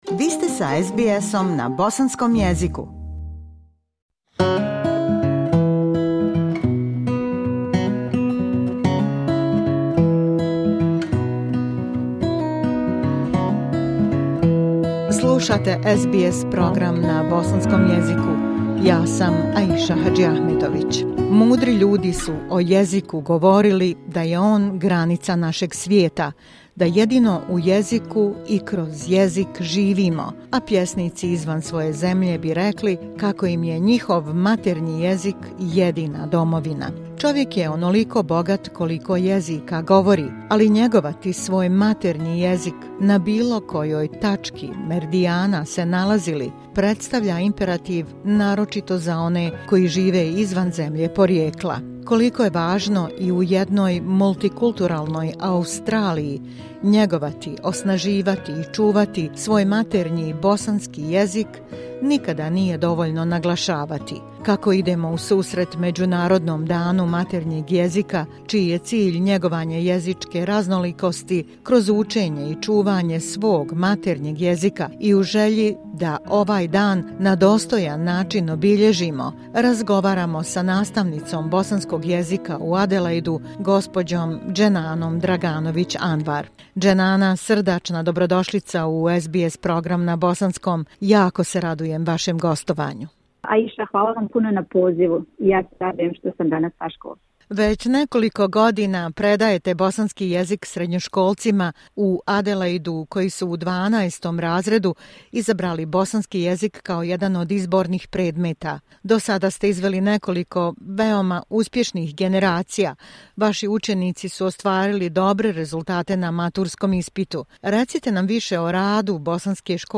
Obilježavajući Međunarodni dan maternjeg jezika koji se u svijetu proslavlja svakog 21. februara donosimo razgovor